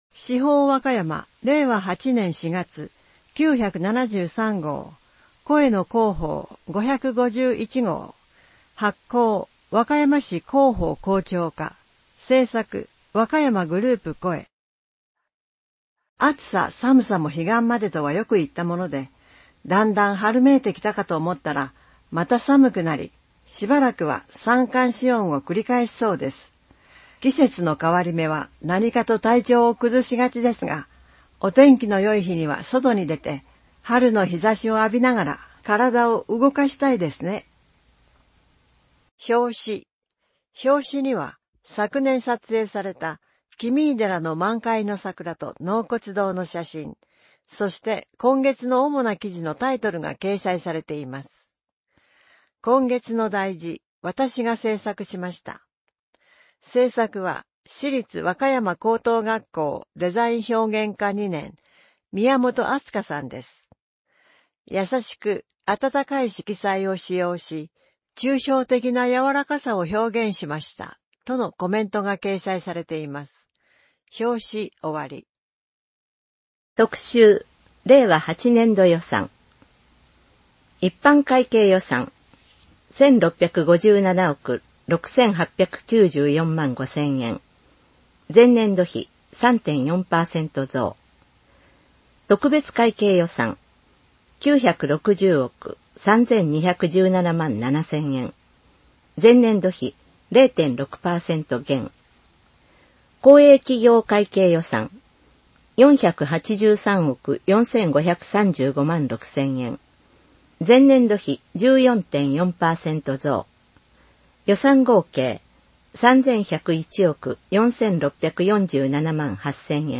市報わかやま 令和8年4月号（声の市報）